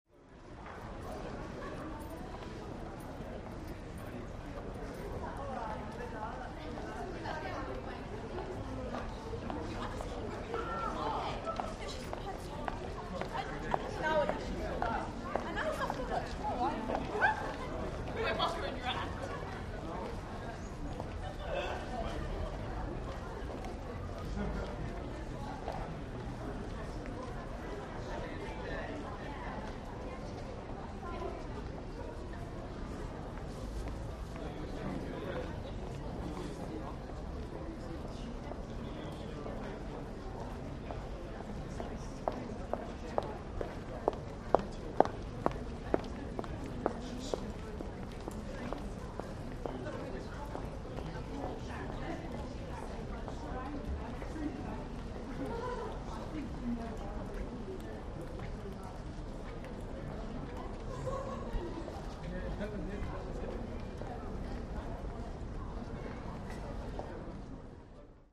City street pedestrians walking Walla ambience